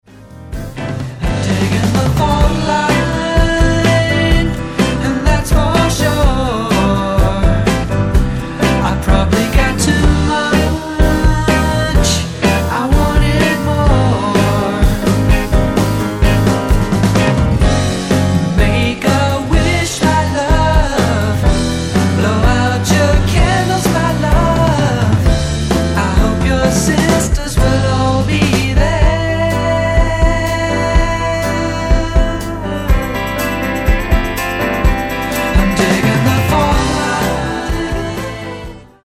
SOFT ROCK/GUITAR POP
ソフトロック、ボサにギターポップまで、全てを取り込み鳴らされる魅惑のポップサウンド。